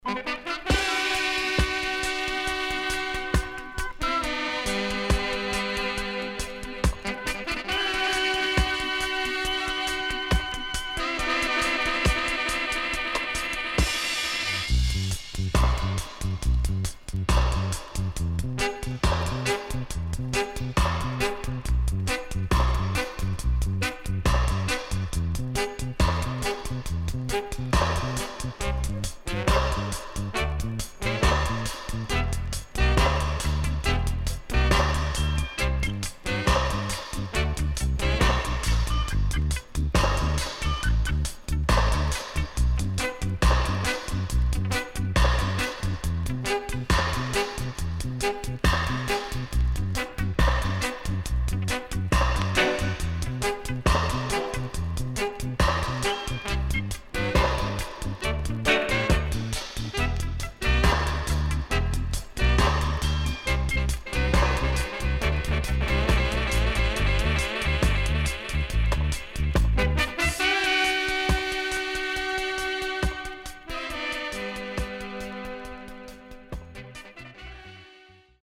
HOME > DISCO45 [VINTAGE]  >  70’s DEEJAY
SIDE A:所々チリノイズがあり、少しプチノイズ入ります。